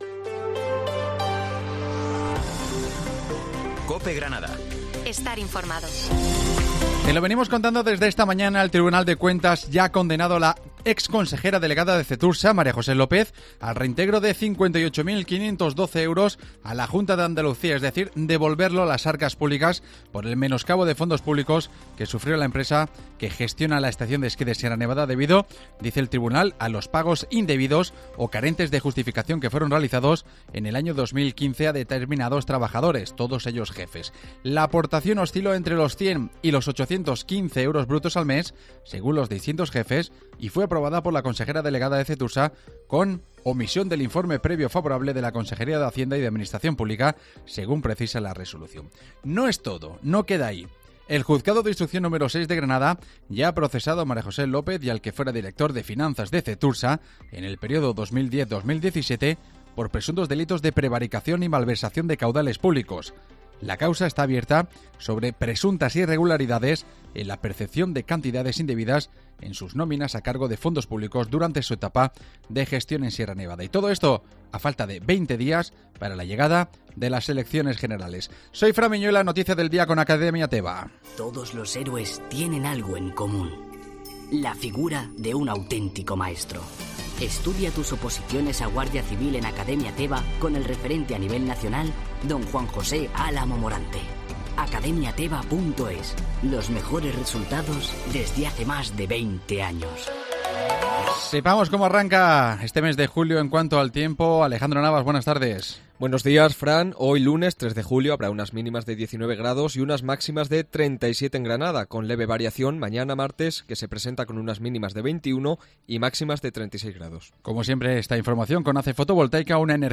informativo Mediodía Granada - 3 Julio